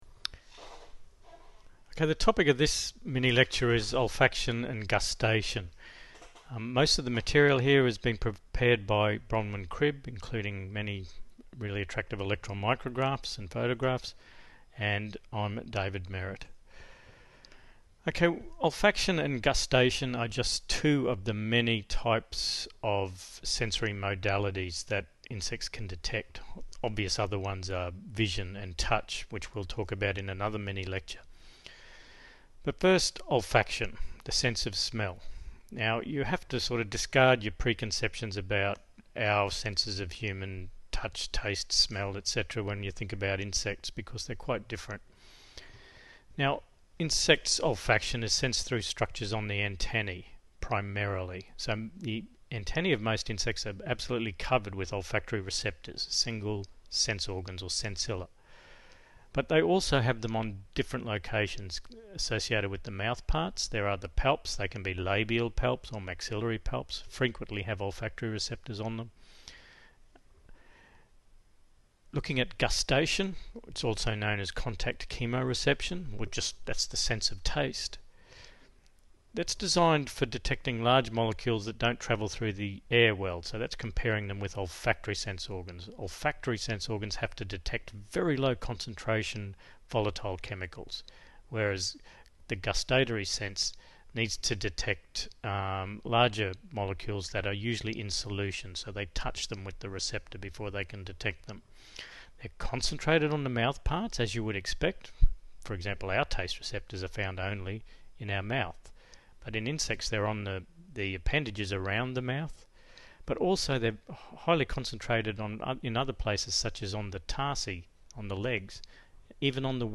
Mini-lecture: